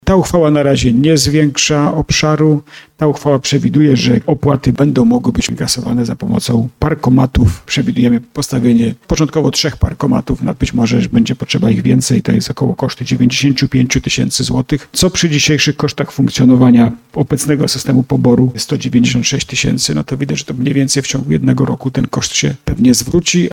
Wciąż strefa płatnego parkowania dotyczy miejsc, które do tej pory były już objęte opłatami – dodał w trakcie sesji burmistrz Jacek Lelek.